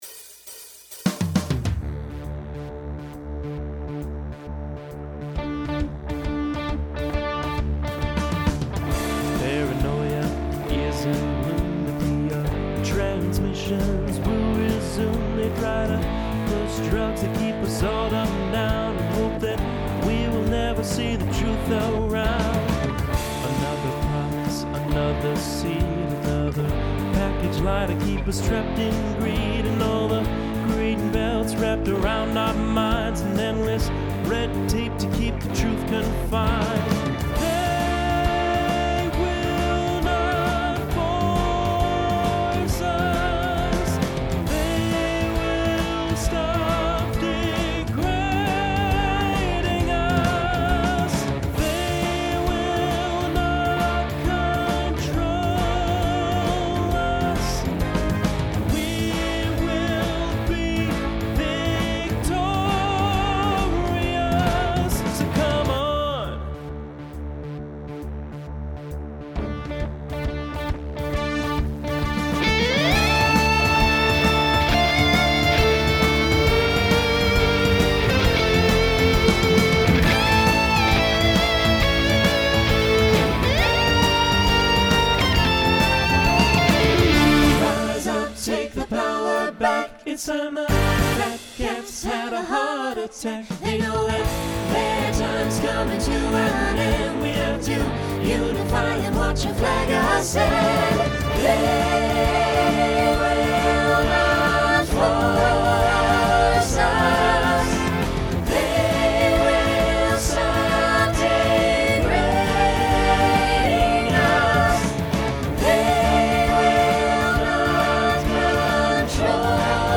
Genre Rock
Transition Voicing Mixed